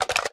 m82_magout_empty.wav